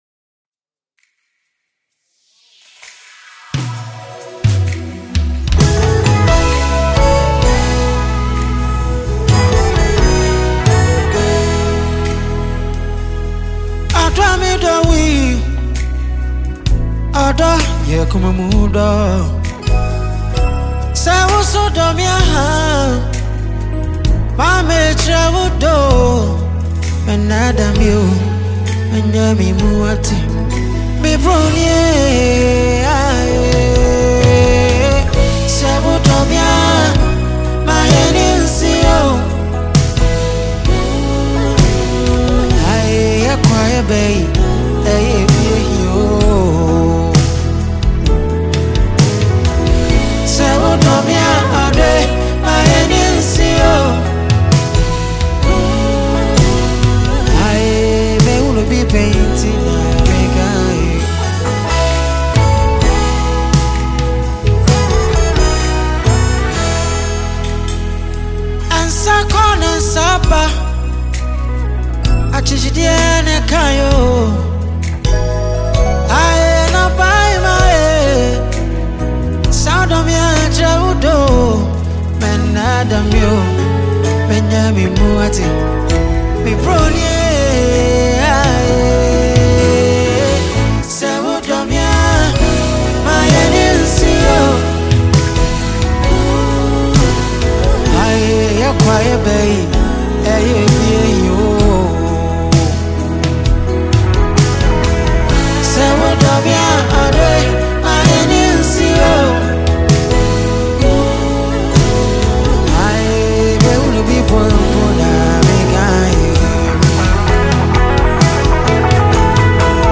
this is a live performance